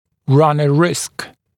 [rʌn ə rɪsk][ран э риск]идти на риск